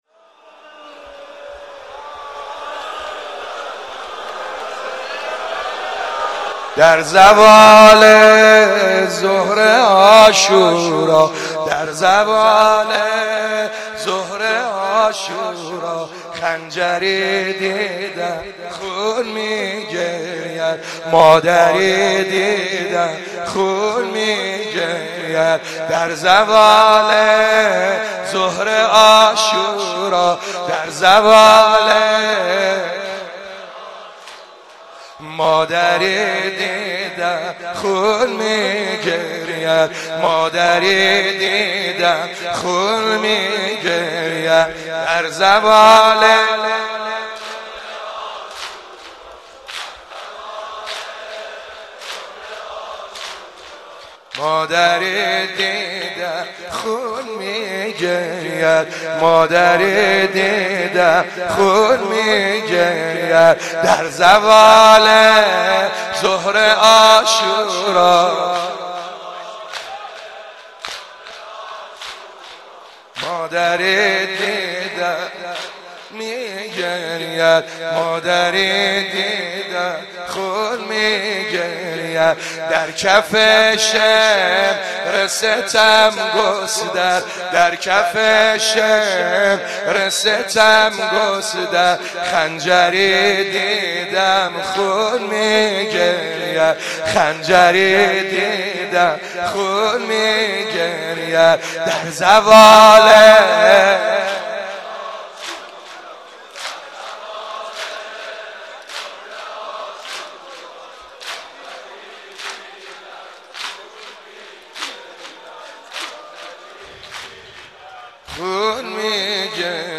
شب دوم محرم